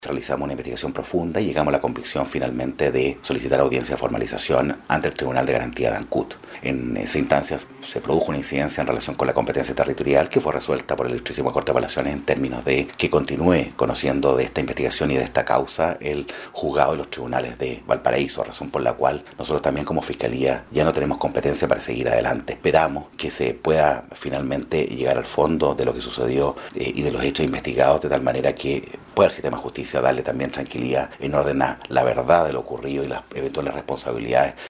El Fiscal Regional, Marcos Emilfork, explicó que la incidencia implica que de ahora en adelante la investigación la lleve adelante el tribunal de Valparaíso.